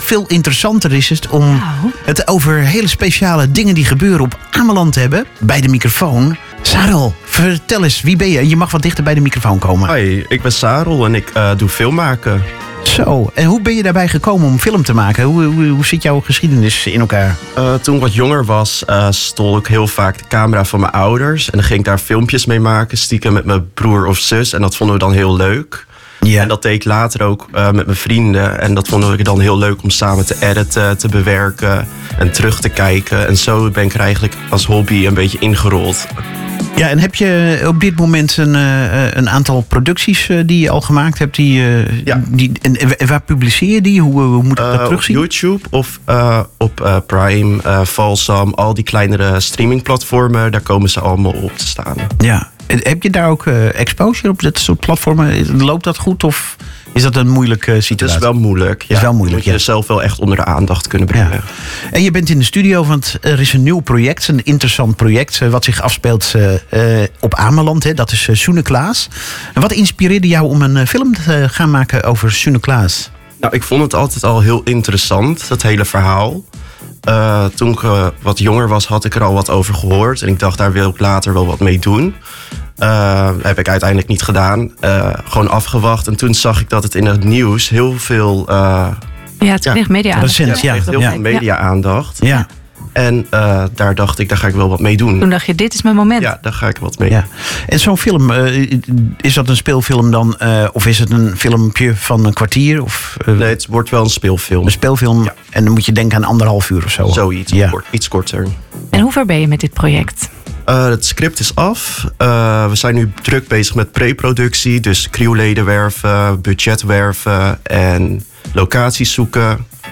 Een bijzondere onthulling tijdens het interview: de radiostudio waar het gesprek plaatsvond, zal ook dienst doen als filmset.